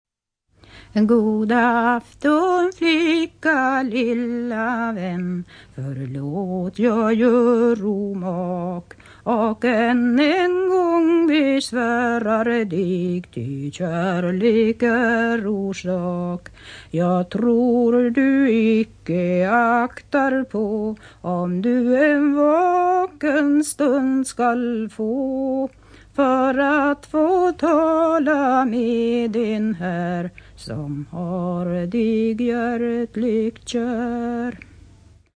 Hennes omfång blir är rätt stort. Hon sjunger med tydliga konsonanter och med en tät klang och en varierad rytmisk gestaltning.
Inspelning: Sveriges Radio,
Typ av visa: lyrisk kärleksvisa